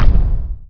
step2.wav